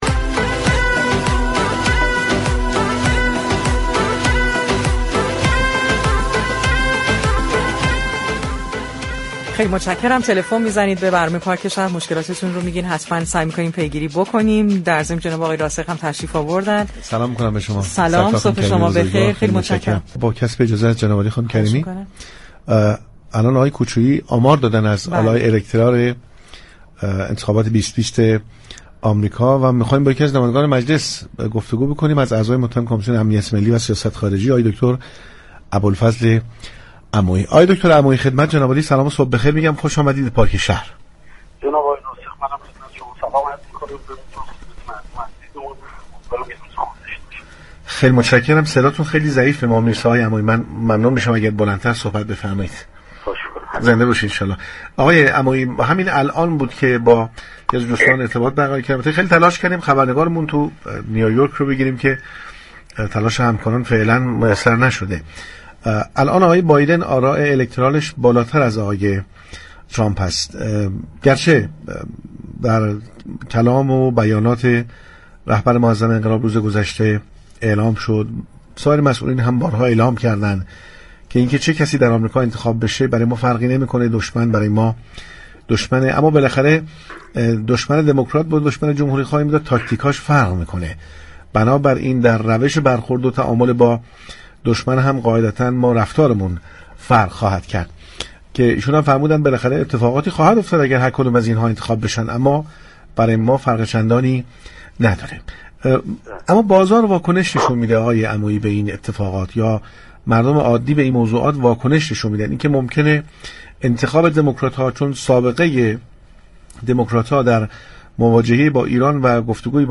به گزارش پایگاه اطلاع رسانی رادیو تهران، ابوالفضل عمویی در گفتگو با برنامه پارك شهر درباره نتیجه نهایی انتخابات ریاست جمهوری آمریكا و تاثیر آن بر سیاست های كشورمان گفت: در ابتدا باید به این نكته اشاره كرد كه آمار قطعی كه تا به این لحظه اعلام شده مربوط به ایالت هایی است كه به صورت سنتی جمهوری خواه و یا دموكرات بوده و آرائشان مشخص است.